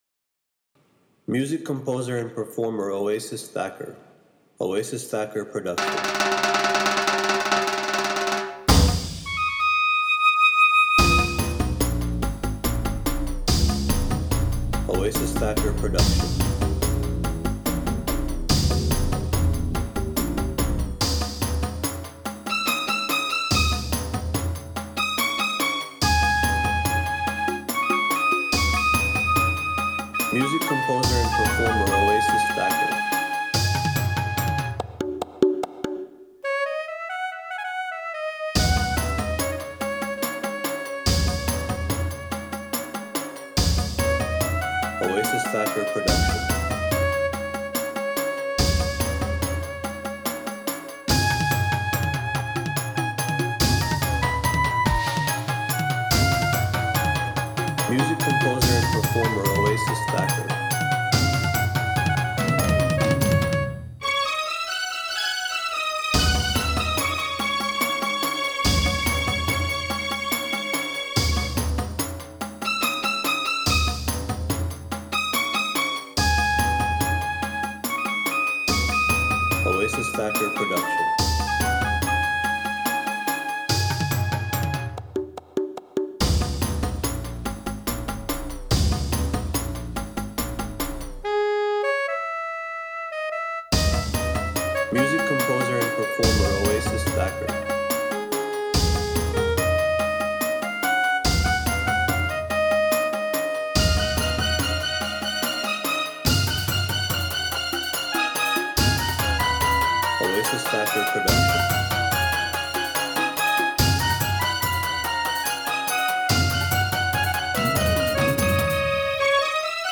Instrumental Music of